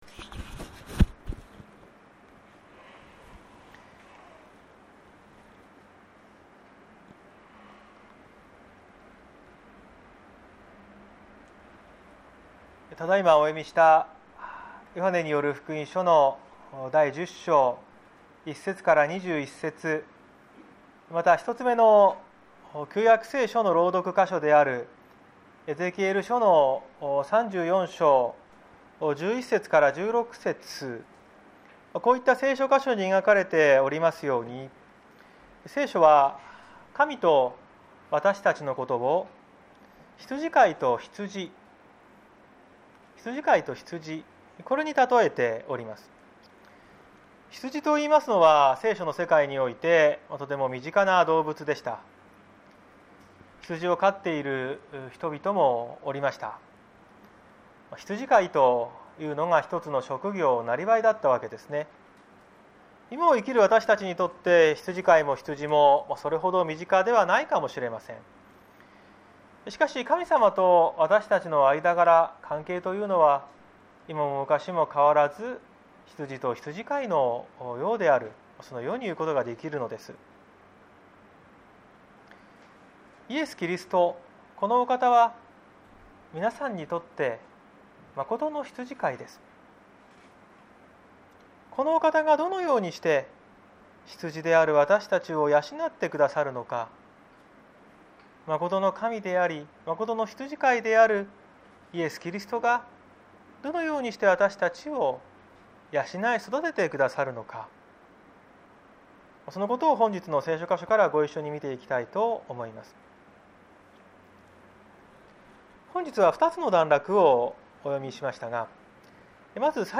2021年06月27日朝の礼拝「愛する者のための命」綱島教会
説教アーカイブ。